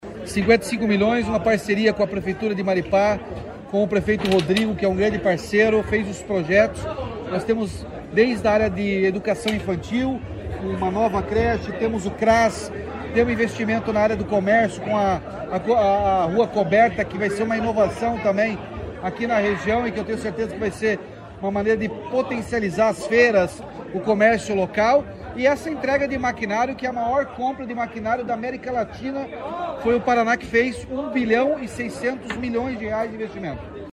Sonora do governador Ratinho Junior sobre o pacote de investimentos anunciado para Maripá